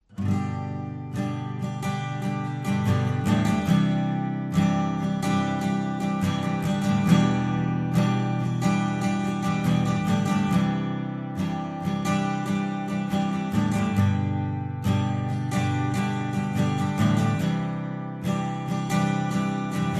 Acoustic Guitar Strum
Acoustic Guitar Strum is a free music sound effect available for download in MP3 format.
056_acoustic_guitar_strum.mp3